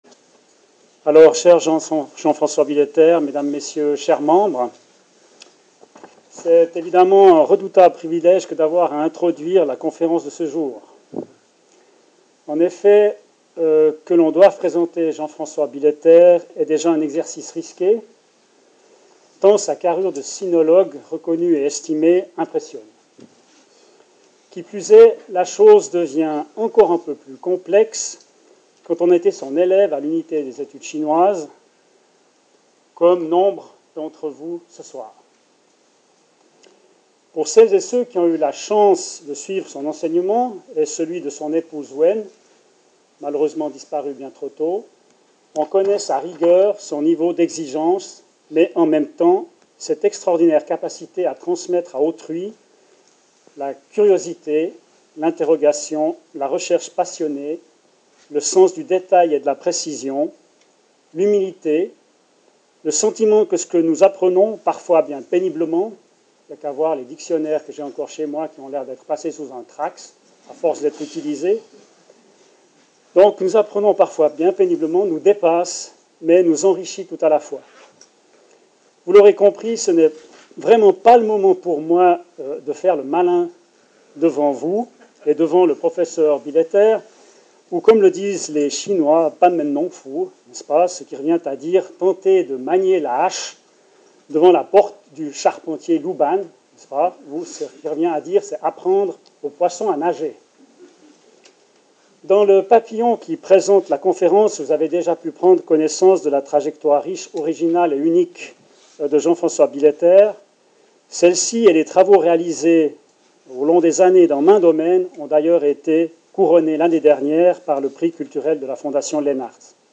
Salle comble pour la conférence donnée par M. Jean François BILLETER, professeur honoraire de l’Université de Genève, ancien titulaire de la chaire de langue, littérature et civilisation chinoises.